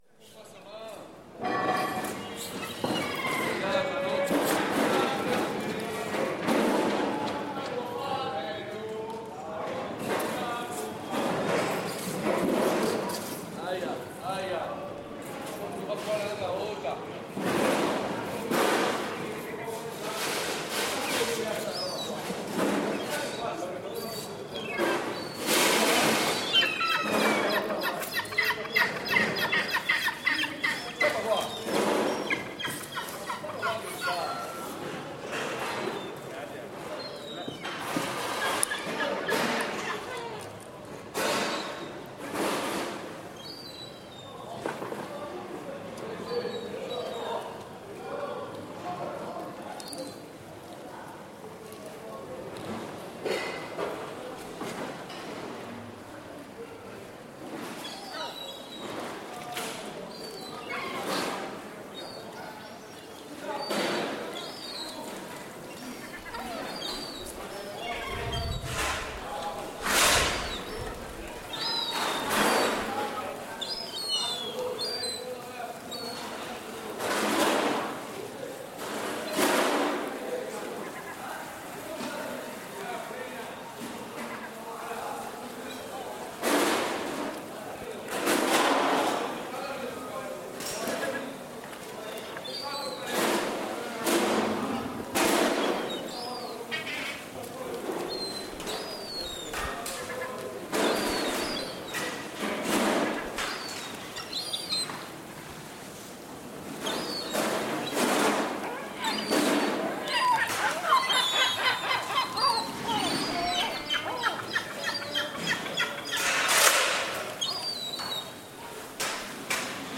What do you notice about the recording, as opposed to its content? Rialto market at closing time